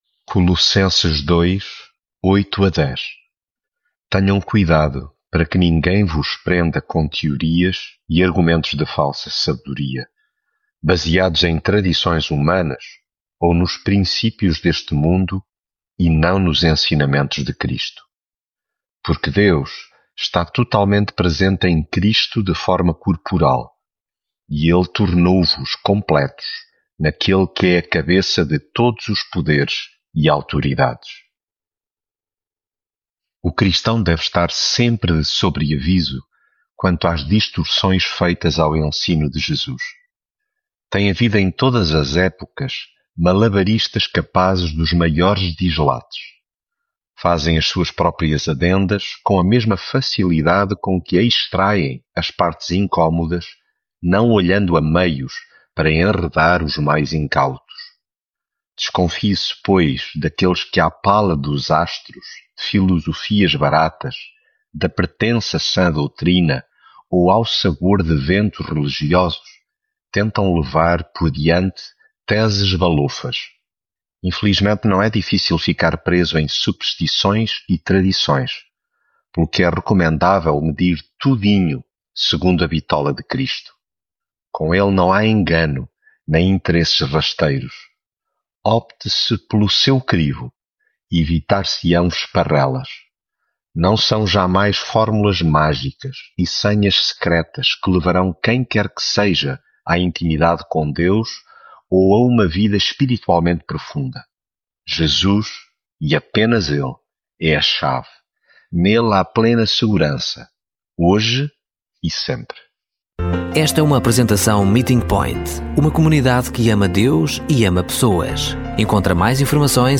Devocional
Leitura em Colossenses 2.8-10